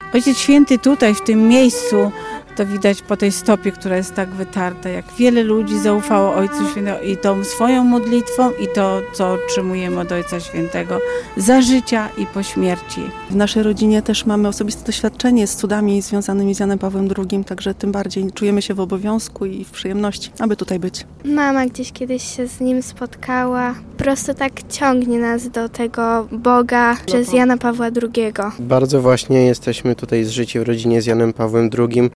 Punktualnie o godzinie 21.37 bialczanie odśpiewaniem Barki uczcili pamięć Św. Jana Pawła II. W 20. rocznicę śmierci papieża Polaka wierni zgromadzili się pod jego pomnikiem przy kościele św. Antoniego. Nie zabrakło wspólnej modlitwy, składania kwiatów i zapalonych zniczy.